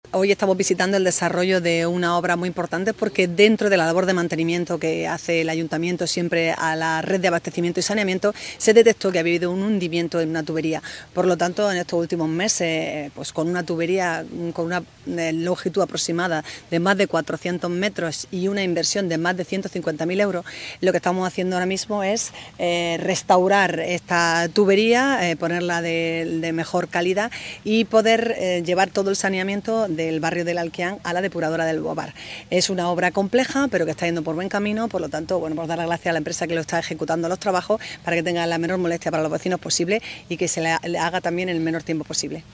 ALCALDESA-COLECTOR-SANEAMIENTO.mp3